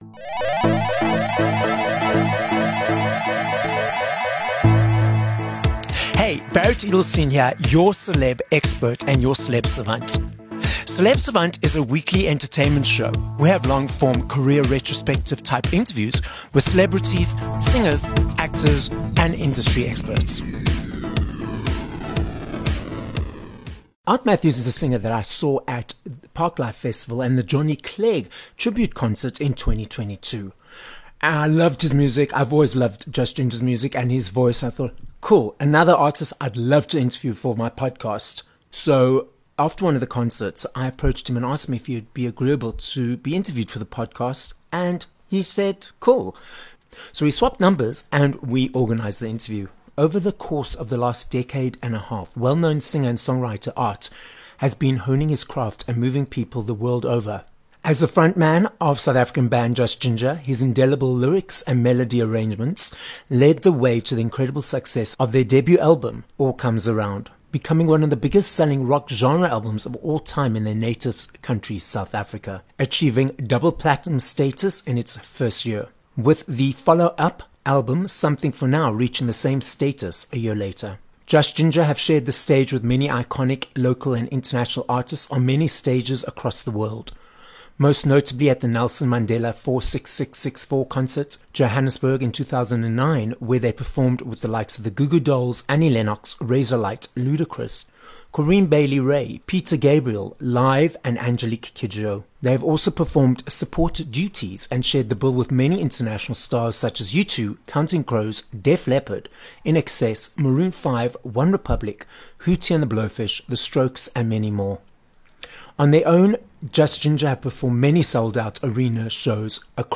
26 Feb Interview with Ard Matthews